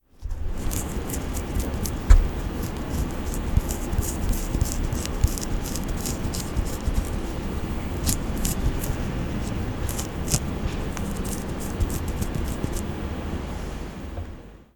과즙팡팡_오렌지까는소리.mp3